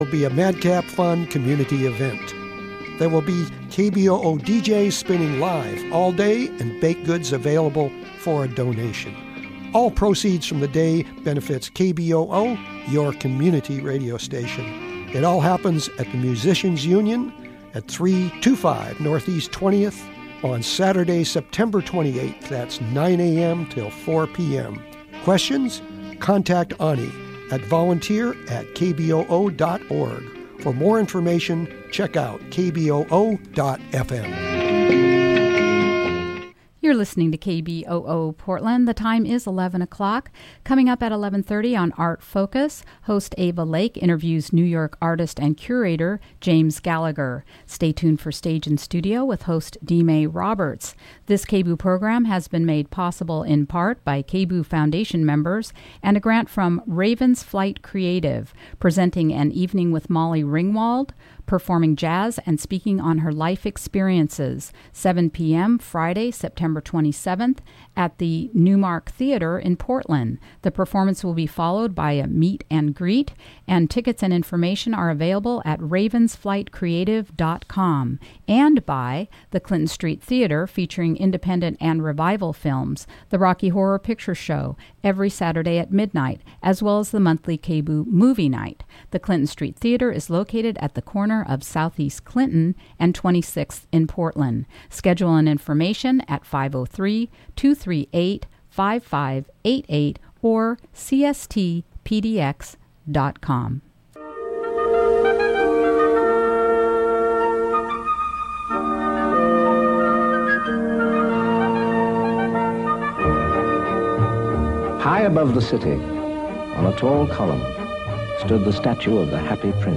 We'll also hear music from Third Angle New Music Ensemble preparing for their TBA performances.